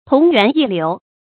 同源異流 注音： ㄊㄨㄙˊ ㄧㄨㄢˊ ㄧˋ ㄌㄧㄨˊ 讀音讀法： 意思解釋： 謂起始、發端相同而趨向、終結不同。